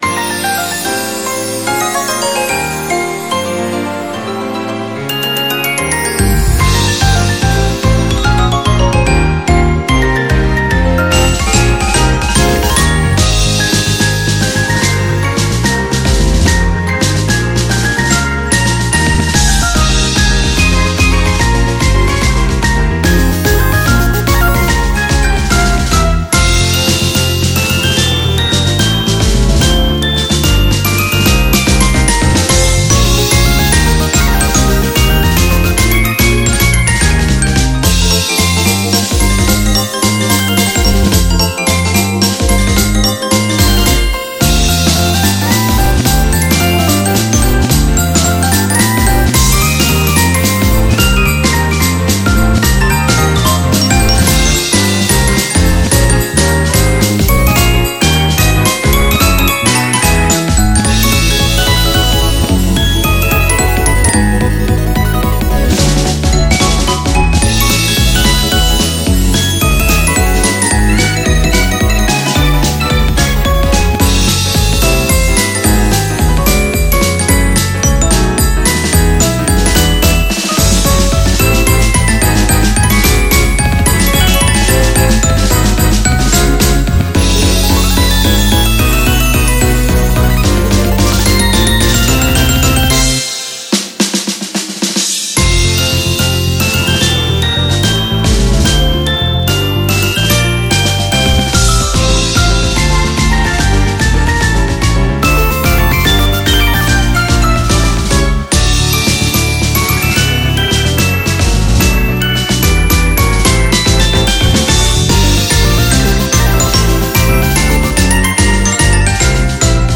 BPM146
Audio QualityLine Out